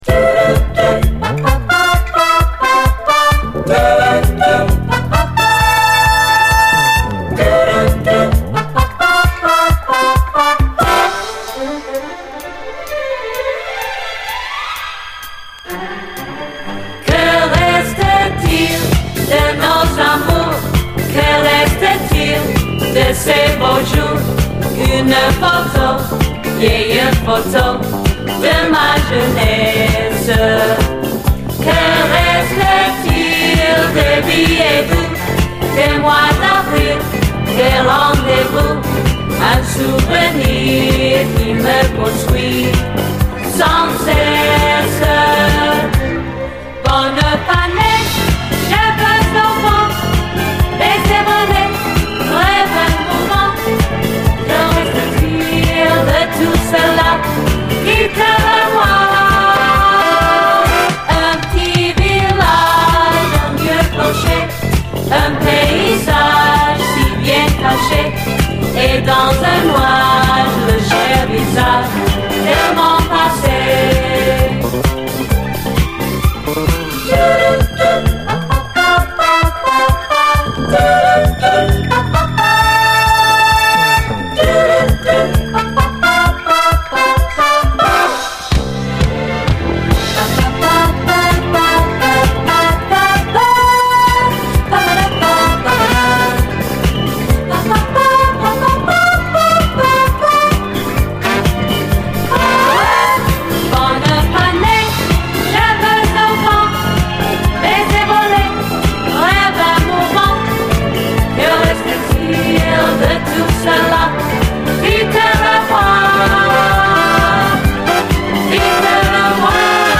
SOUL, 70's～ SOUL, DISCO, WORLD, 7INCH